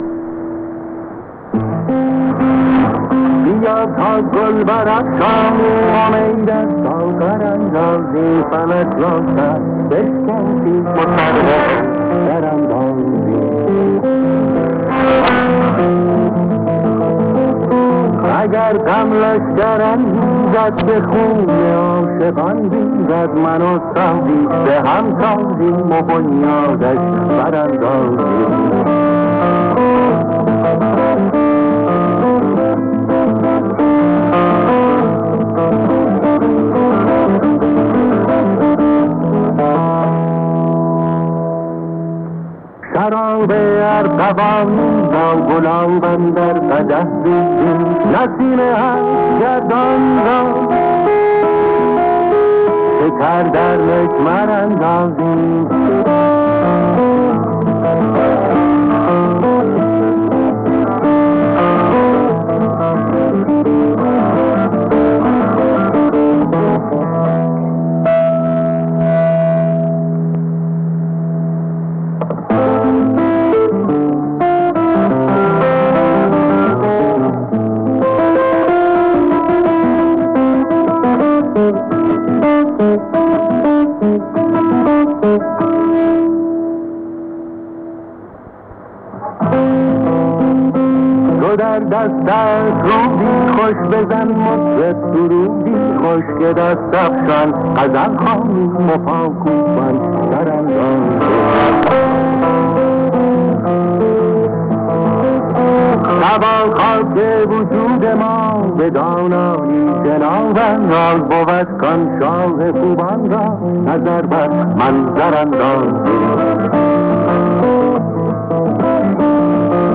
Poslech rádia.
26.4.2017 Language Persian Time (start) 1810 Time (end) 1830 Frequency 7480 MHz SINPO 44533 Place of reception Prague, loc.: JN70EA